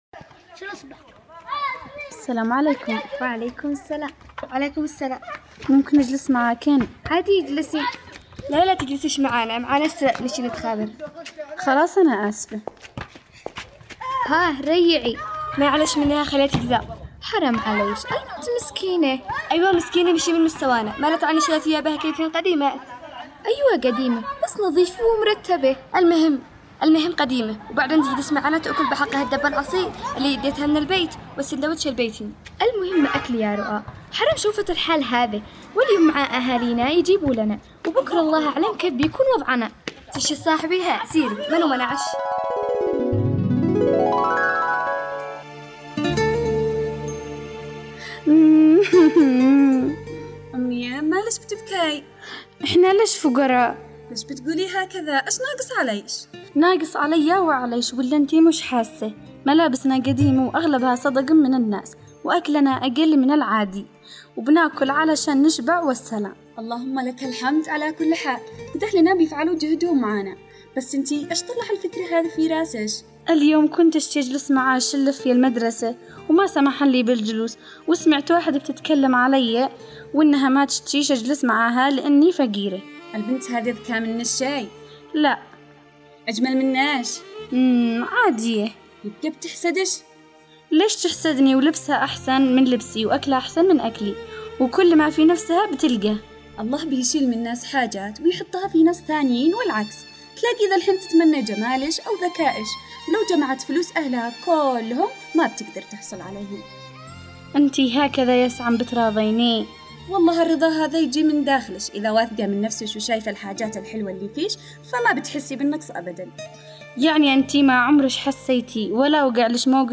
دراما رمضانية